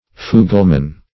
Fugleman \Fu"gle*man\, n.; pl. Fuglemen.